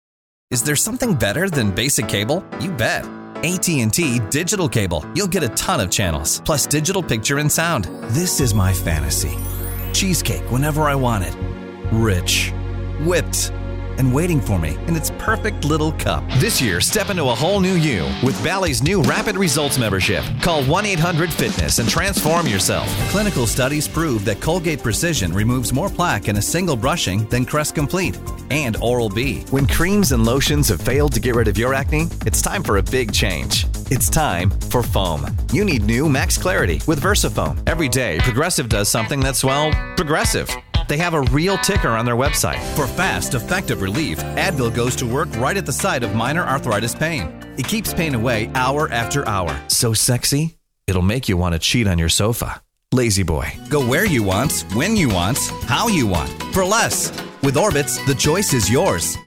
Young, energetic, believable, genuine, real, guy next door
Sprechprobe: Werbung (Muttersprache):